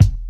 • Old School Deep Kick Sample E Key 116.wav
Royality free bass drum single shot tuned to the E note.
old-school-deep-kick-sample-e-key-116-KZ8.wav